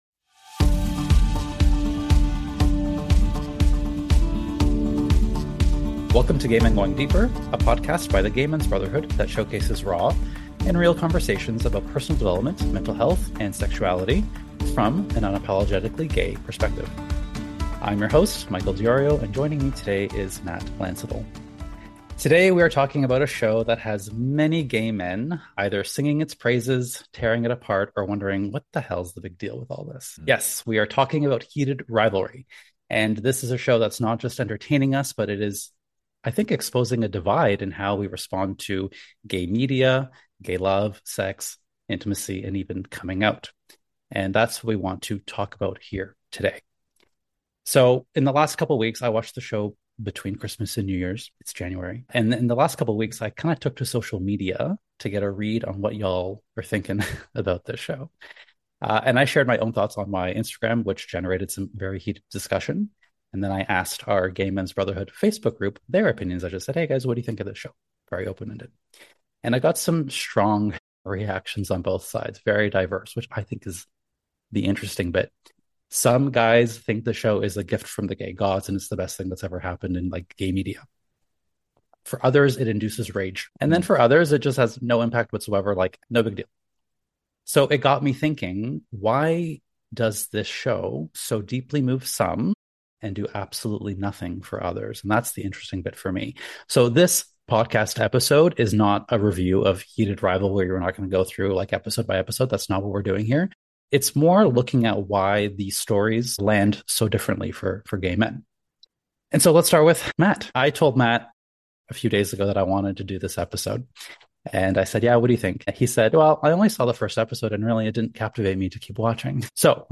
This isn’t a recap or a review. It’s a conversation about why some stories carry more weight than others, and how paying attention to your reaction can become an opportunity to understand yourself more deeply.